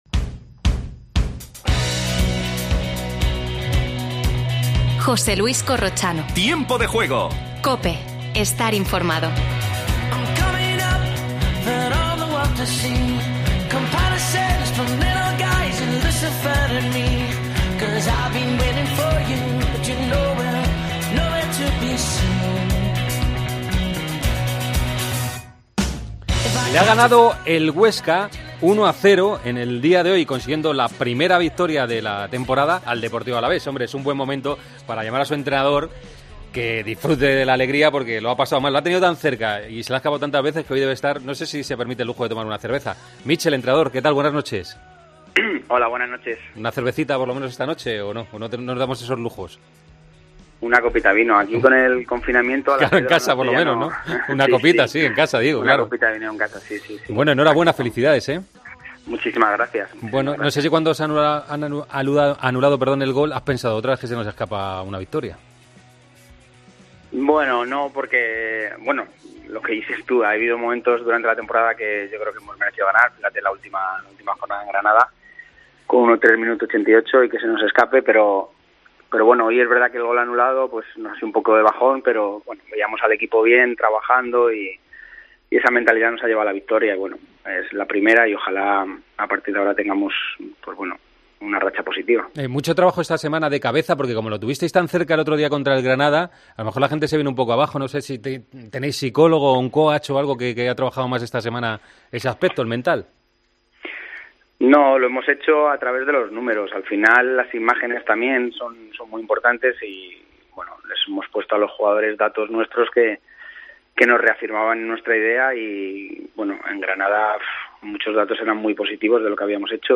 AUDIO: Entrevista a Michel tras la primera victoria del Huesca. El Sevilla ganó al Getafe y Valencia y Athletic empataron.